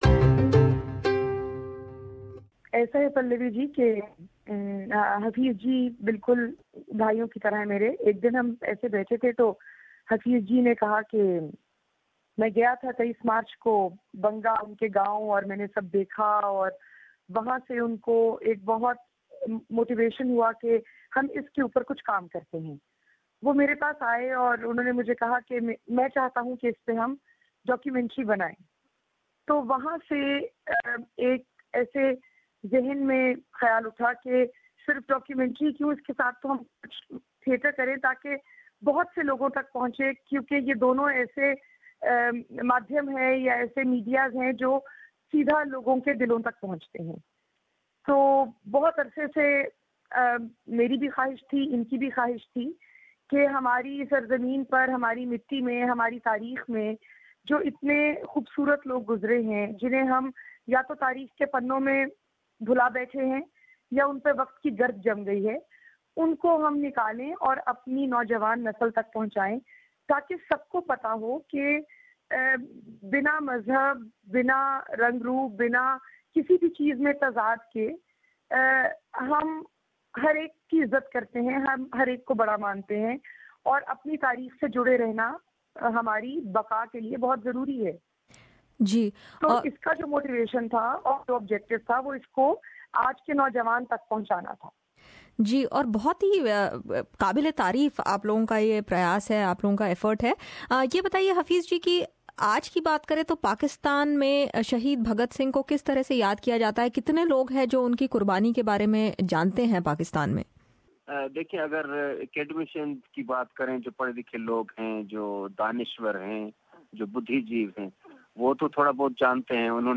We spoke to the duo to know more about this project and what motivated them to initiate it.